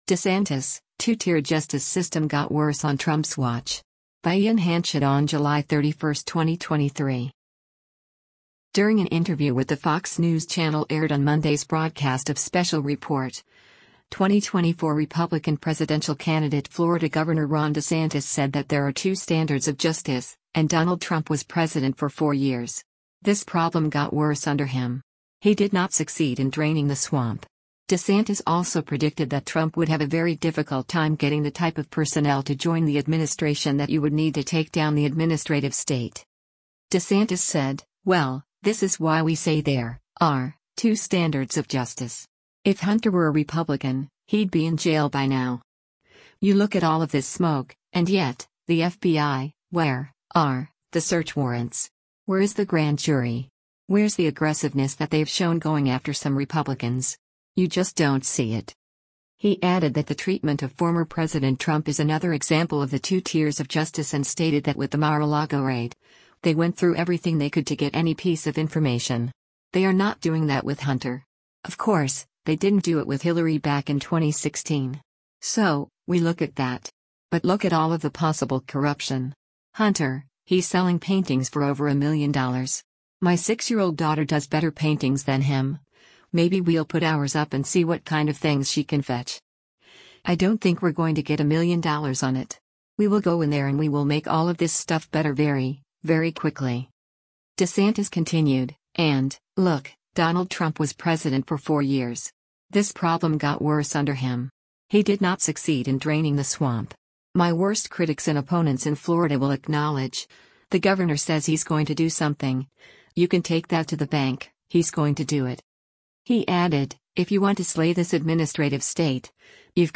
During an interview with the Fox News Channel aired on Monday’s broadcast of “Special Report,” 2024 Republican presidential candidate Florida Gov. Ron DeSantis said that there are two standards of justice, and “Donald Trump was President for four years.